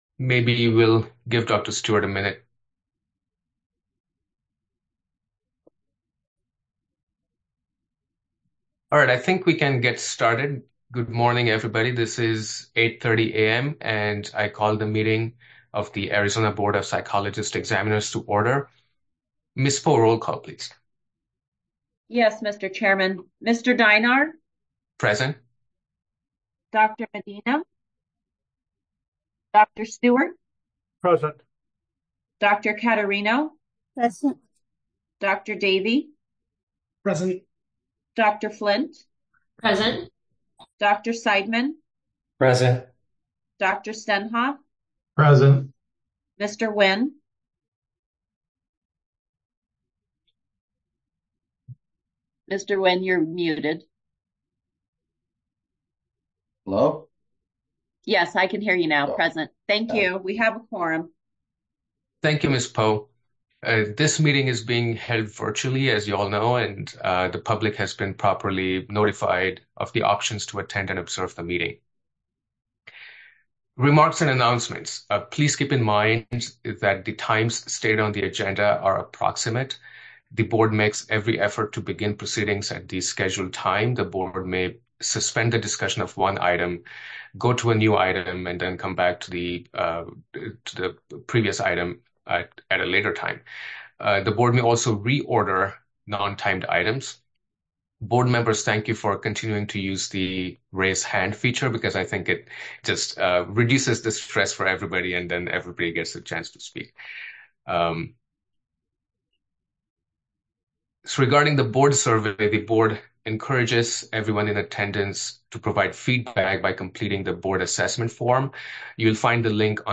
Board Meeting | Board of Psychologist Examiners
Members will participate via Zoom